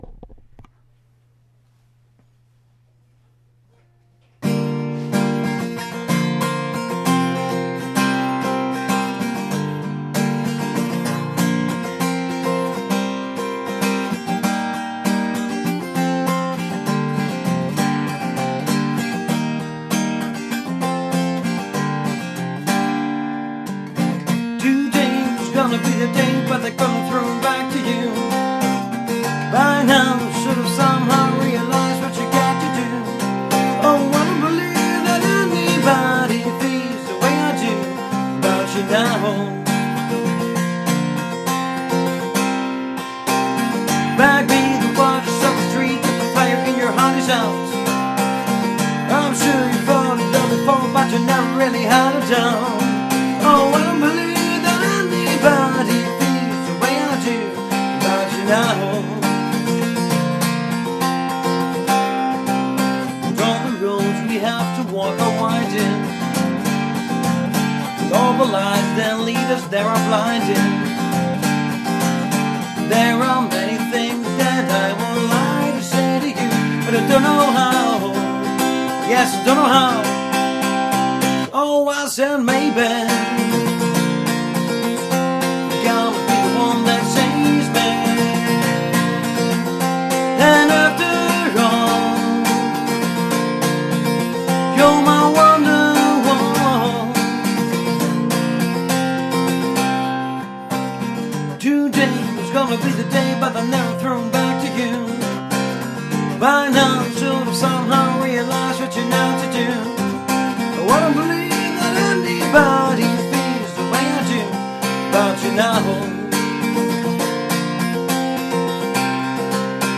Handmade Music, Livemusic mit Gitarre und Gesang
• Unplugged
• Coverband